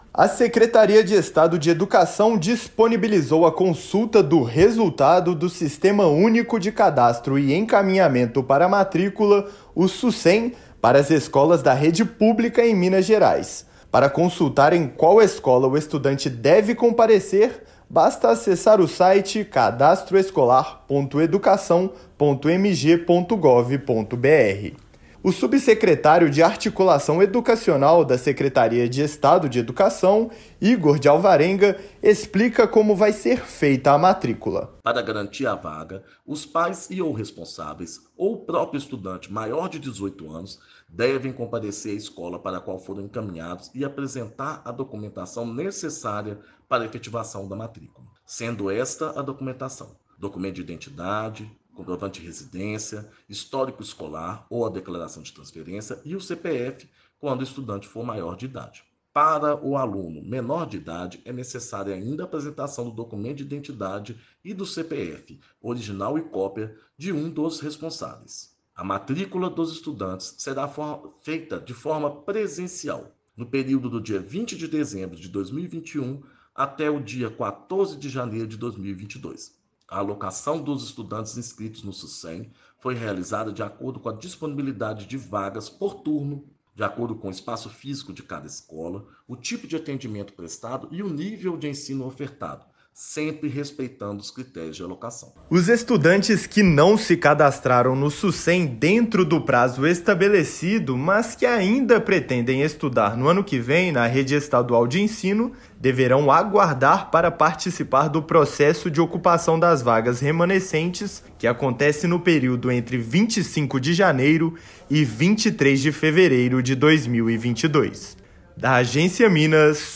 Para garantir a vaga, os pais e/ou responsáveis devem comparecer à escola para a qual foram encaminhados e apresentar a documentação necessária. Ouça a matéria de rádio.